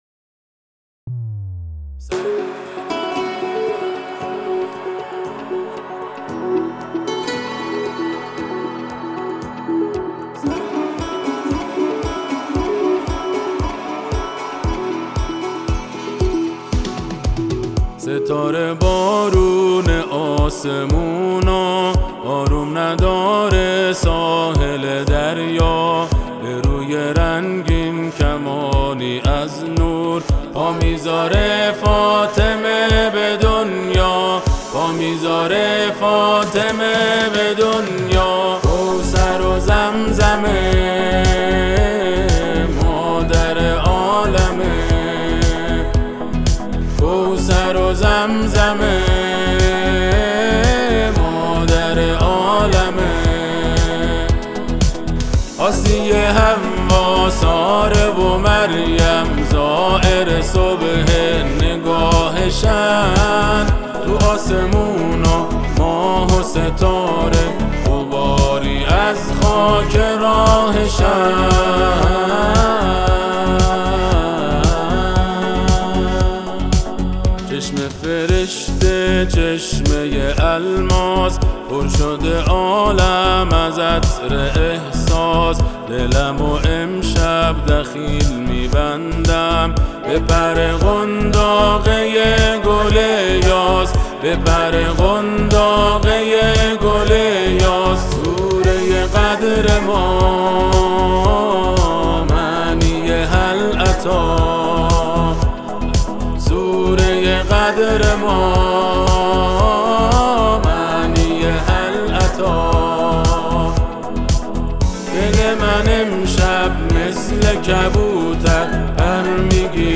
آثار آهنگین مذهبی